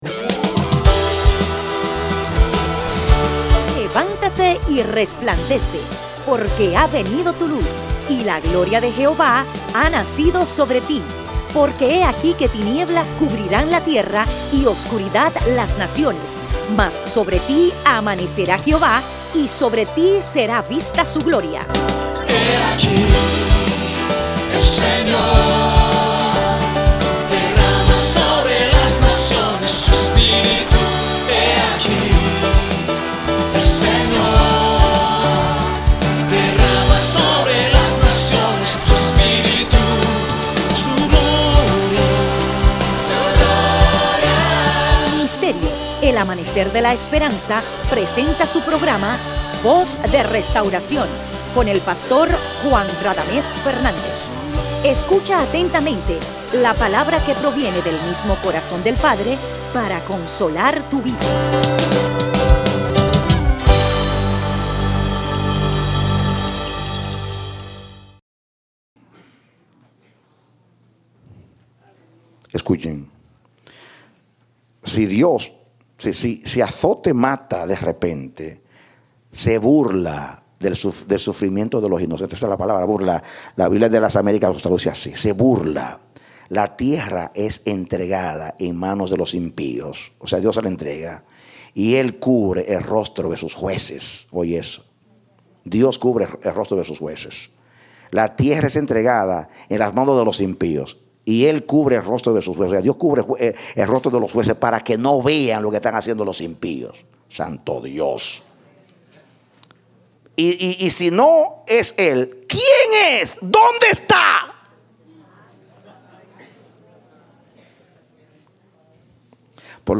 Parte A Serie de 2 Predicado Junio 30, 2013